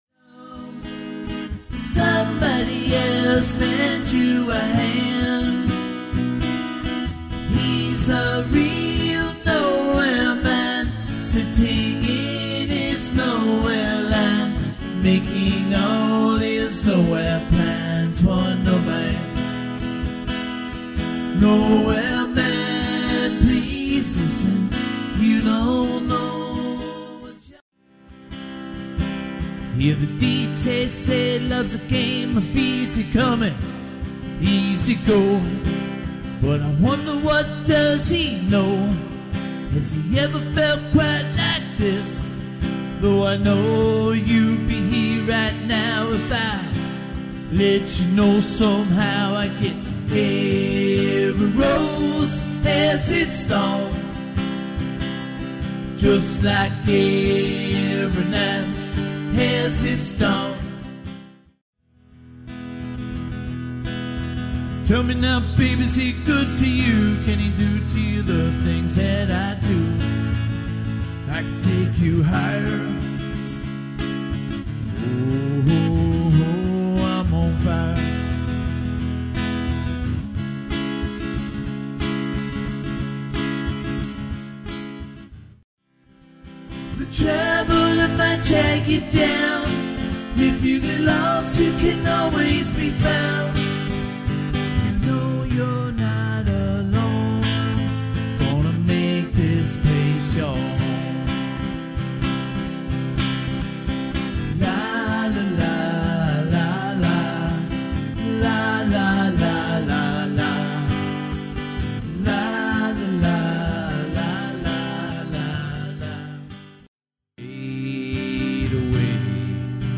Acoustic Music (solo/duo/trio/quartet)